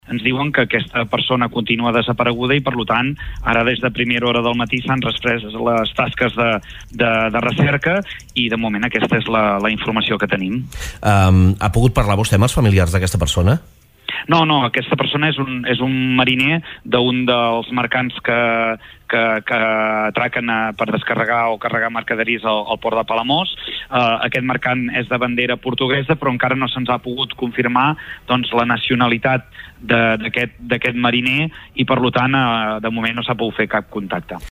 08.36 L’alcalde de Palamós, Lluís Puig, explica al Supermatí de Ràdio Capital que el mariner desaparegut al Port anava a un vaixell amb bandera portuguesa.